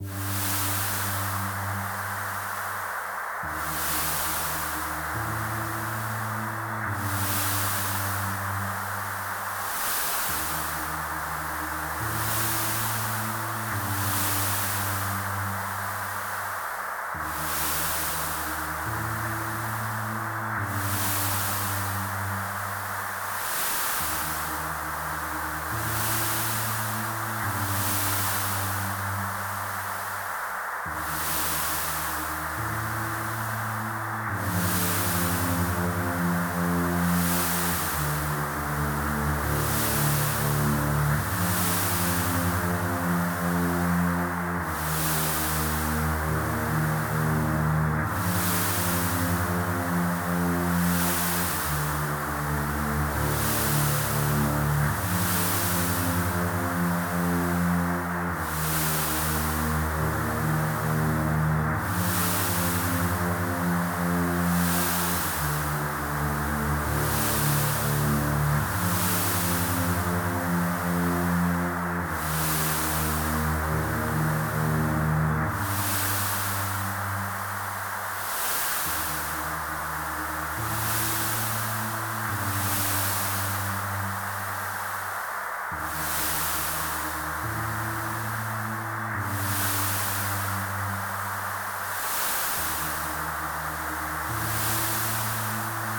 ambience.ogg